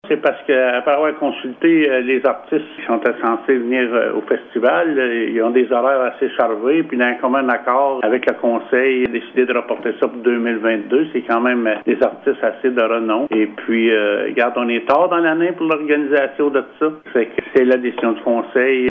Réal Rochon, maire de Gracefield, assure que son équipe a analysé tous les scénarios possibles avant d’en arriver à ce verdict.